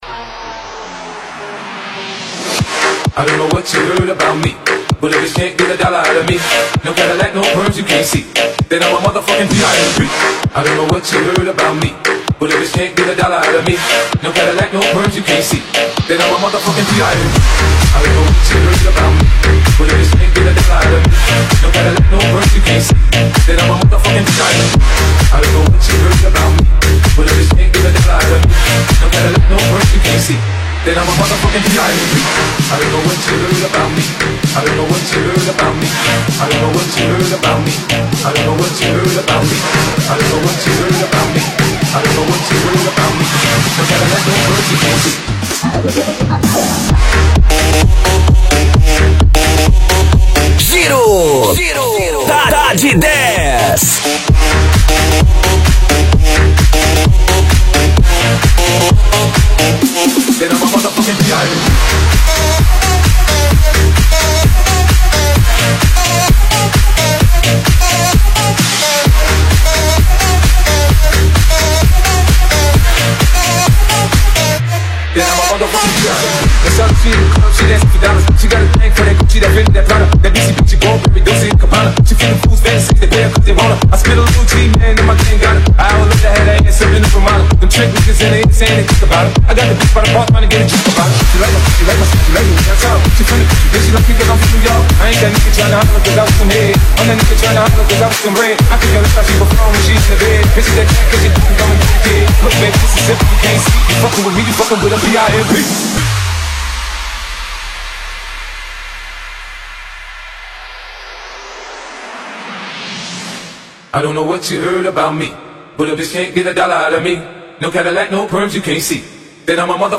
são dez musicas na sequencia sem sai de cima